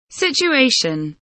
situation kelimesinin anlamı, resimli anlatımı ve sesli okunuşu